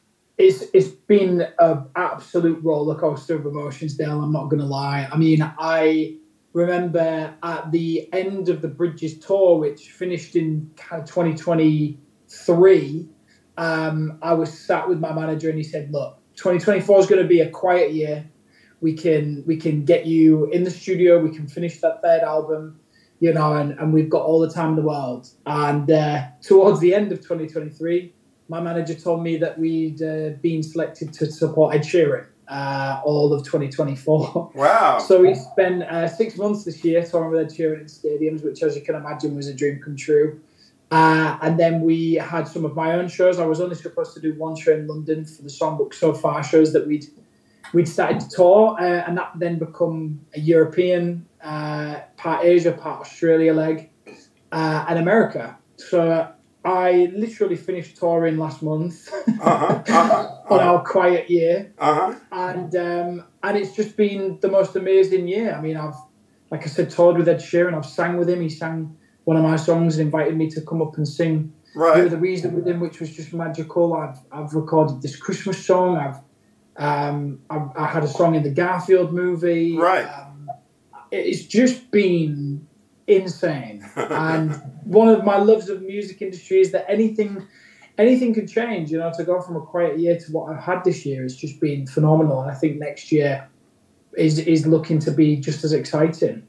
Here’s an excerpt of our interview with Calum Scott, who talks about his busy year in 2024, releasing new songs and touring with Ed Sheeran in the past year.
Calum_Scott_interview_excerpt.mp3